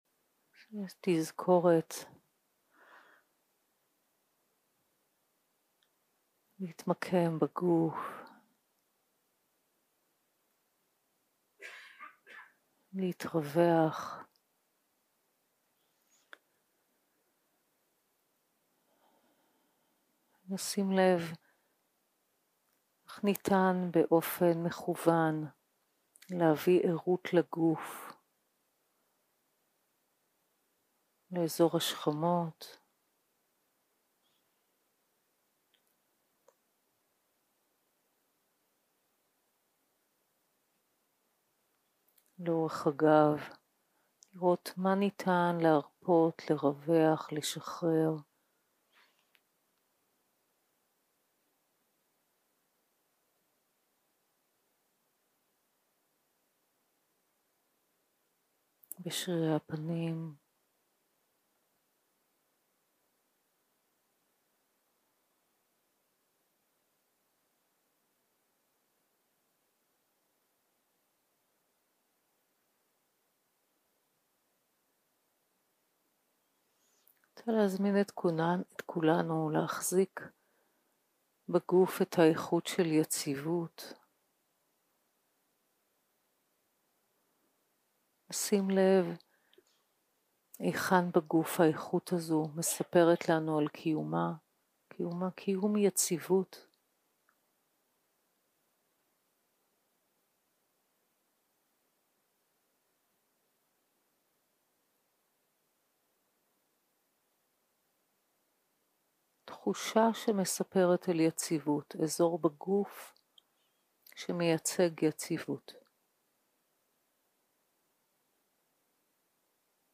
יום 7 - הקלטה 17 - צהרים - מדיטציה מונחית - מטא לעצמי ולדמות המיטיבה